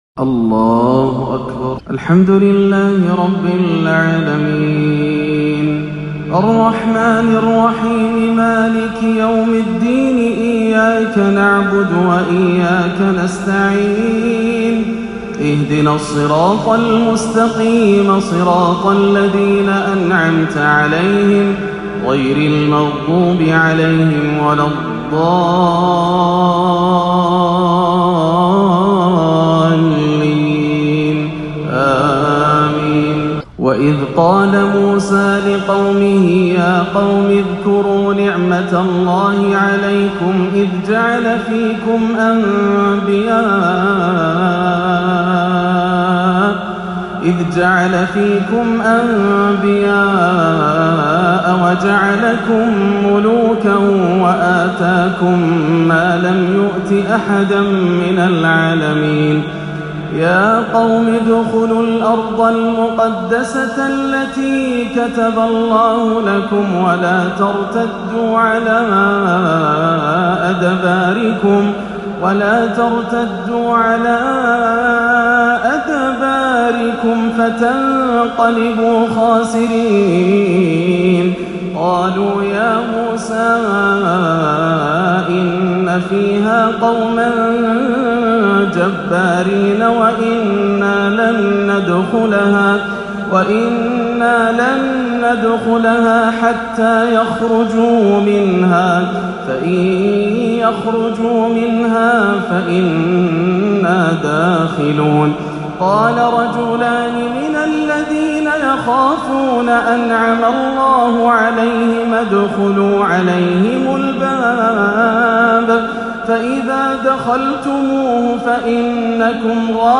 (فطوعت له نفسه قتل أخيه) تلاوة رائعة بالبيات المحبر والحزين من سورة المائدة - الأربعاء 12-11 > عام 1439 > الفروض - تلاوات ياسر الدوسري